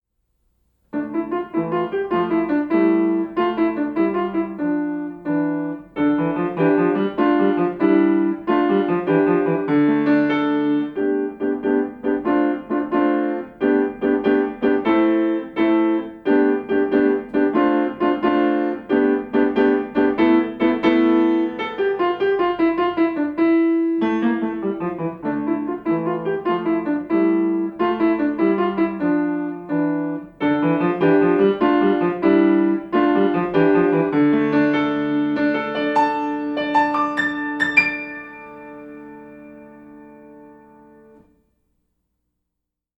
lively, lilting, late elementary piece in 6/8 meter